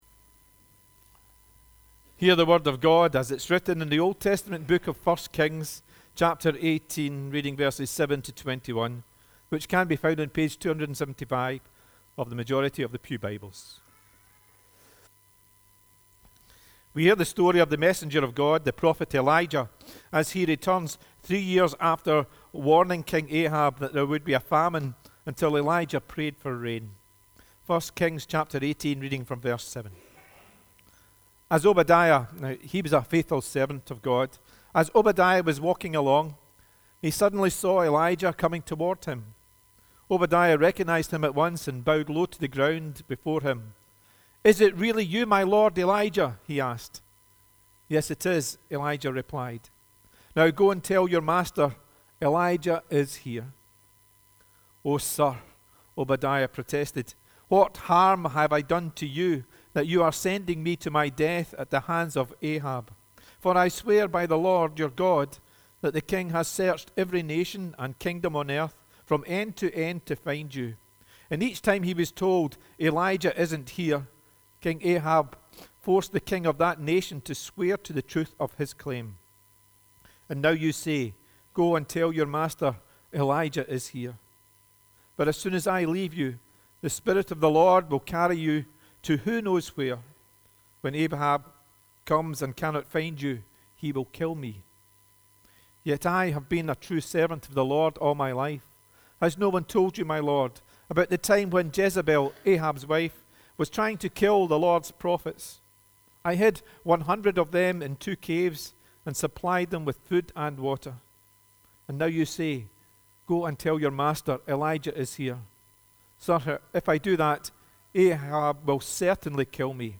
The reading prior to the sermon is 1 Kings 18: 7-21